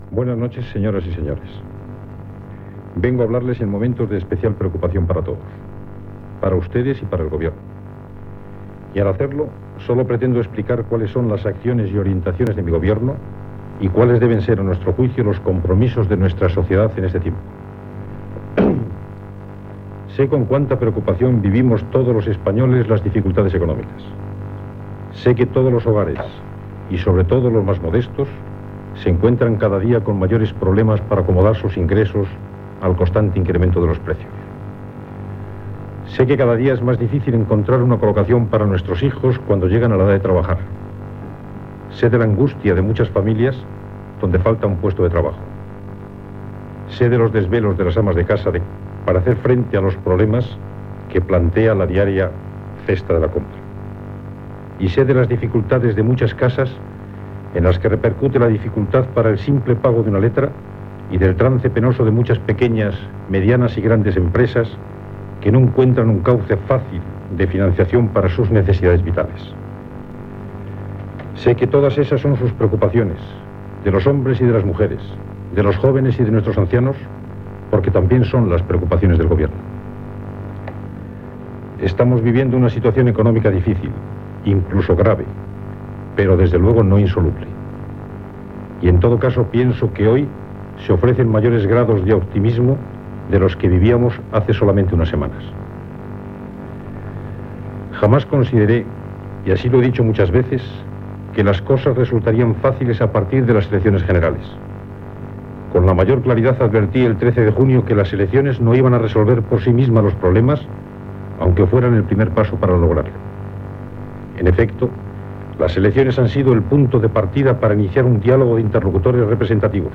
Discurs del president del goven espanyol Adolfo Suárez sobre l'atur, la cris econòmica i les mesures que es prendran
Informatiu